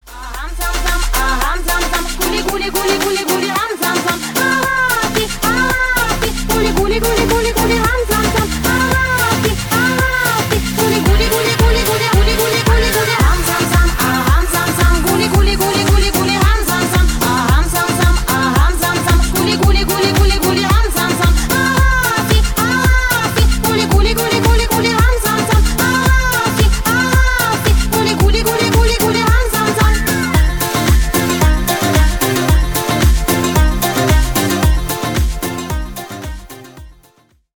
• Качество: 320 kbps, Stereo
Танцевальные
весёлые
без слов